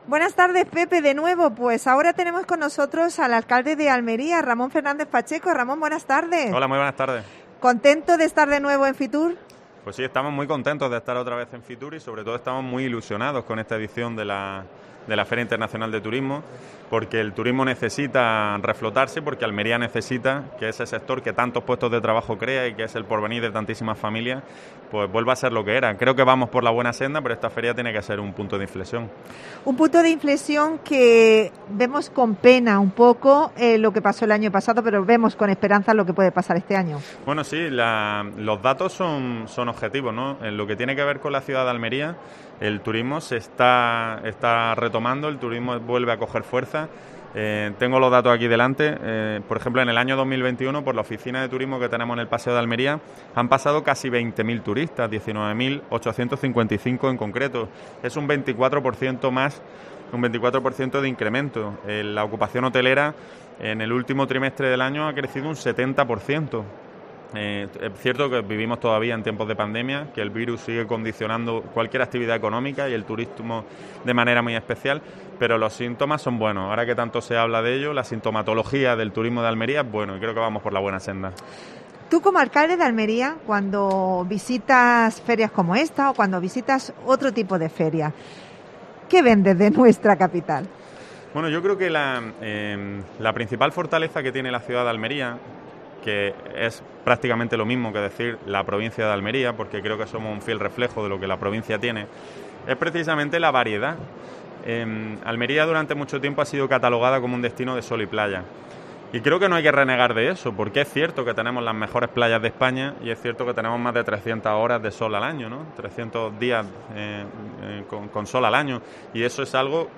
La capital almeriense ofrece a los visitantes su mejor 'sonrisa'. El alcalde ha cerrado el primer día de los programas especiales que COPE Almería realiza en FITUR.